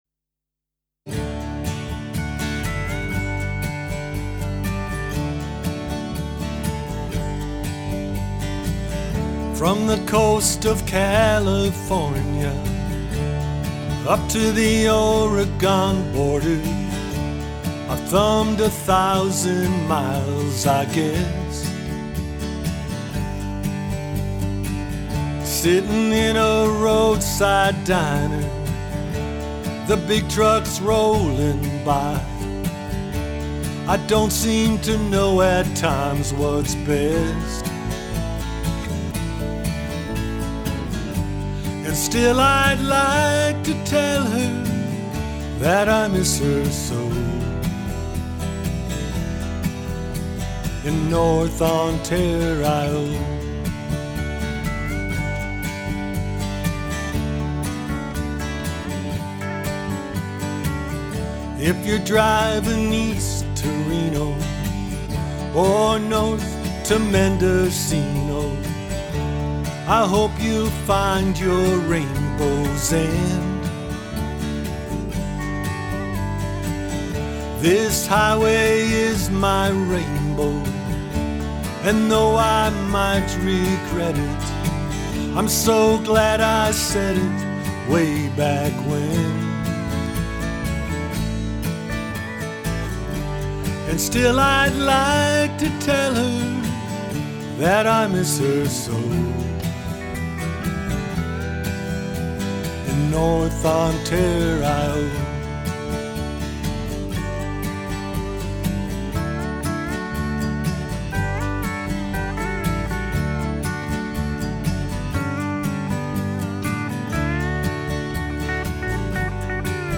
This is a truck driver song.